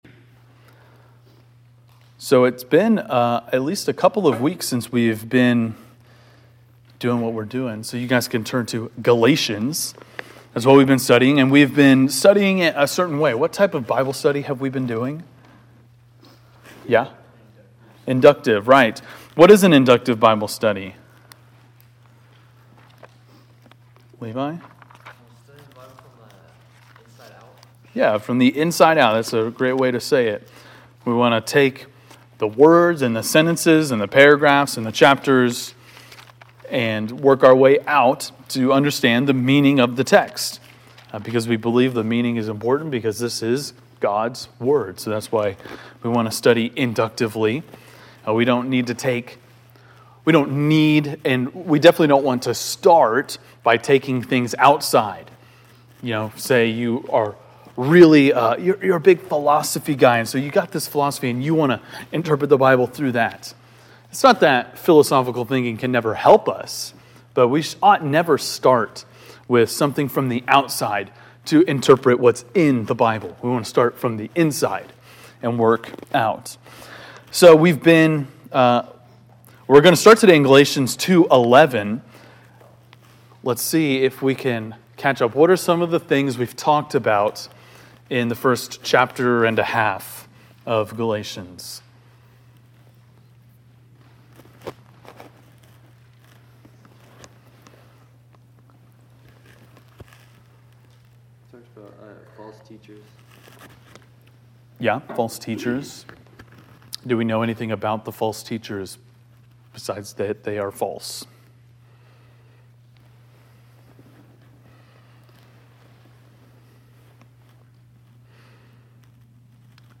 Galatians 2:11-14 (Inductive Bible Study)